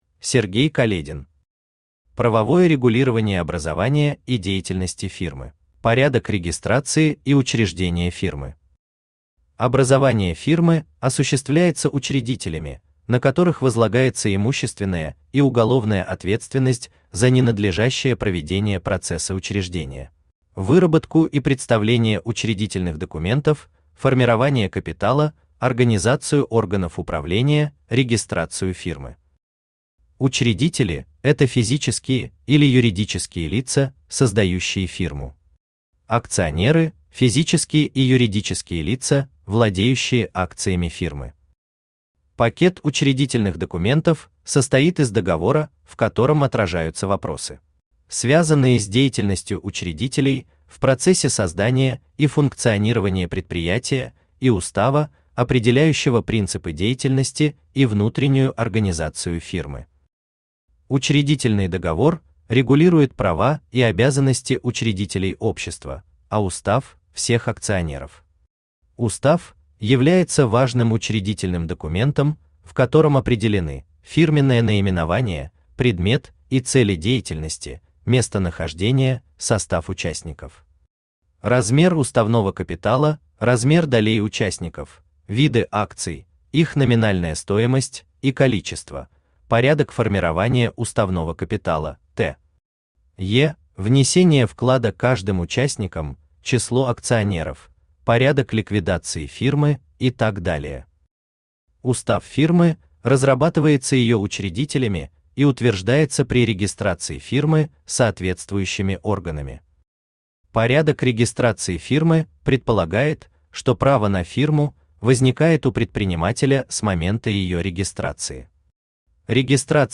Аудиокнига Правовое регулирование образования и деятельности фирмы | Библиотека аудиокниг
Aудиокнига Правовое регулирование образования и деятельности фирмы Автор Сергей Каледин Читает аудиокнигу Авточтец ЛитРес.